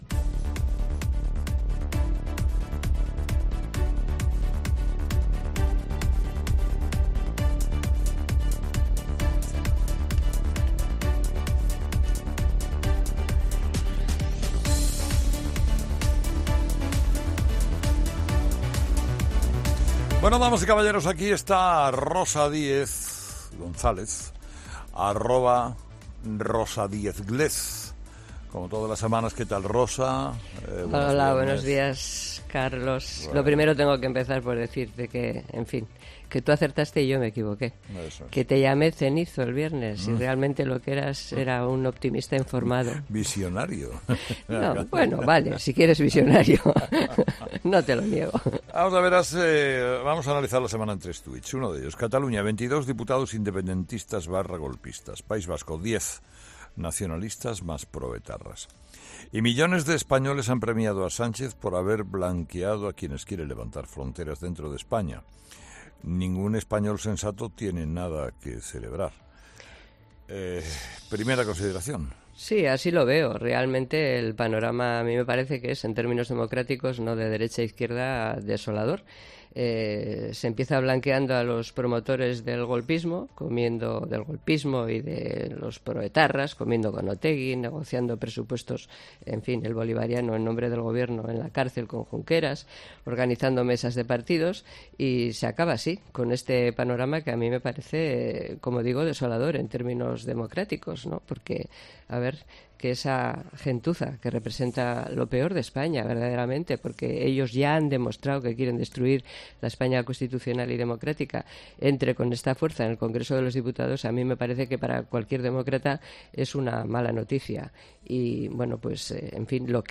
Este viernes, como todos, la exlíder de UPyD ha estado con Herrera repasando esta intensa semana.